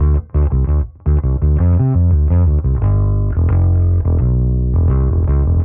Index of /musicradar/dusty-funk-samples/Bass/85bpm
DF_PegBass_85-D.wav